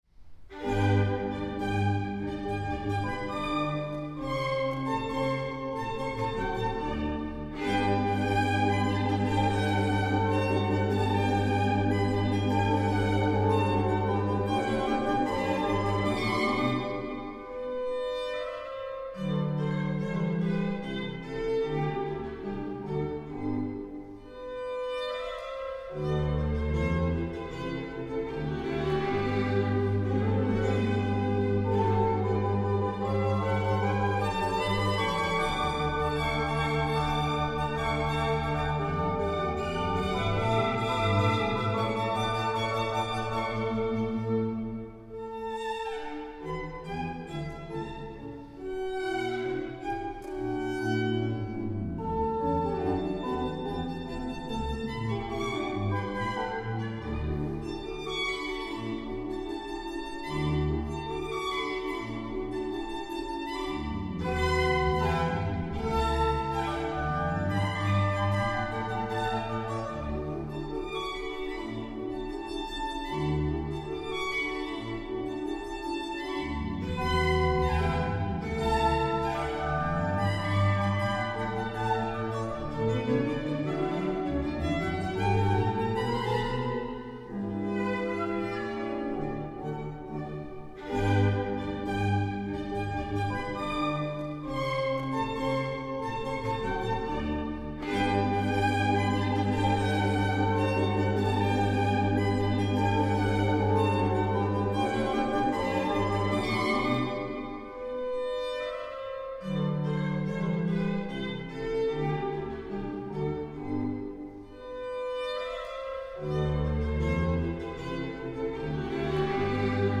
von Barock bis Jazz
Violine/Tenor
Orgel/Bariton